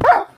Minecraft Version Minecraft Version 1.21.5 Latest Release | Latest Snapshot 1.21.5 / assets / minecraft / sounds / mob / wolf / classic / hurt2.ogg Compare With Compare With Latest Release | Latest Snapshot
hurt2.ogg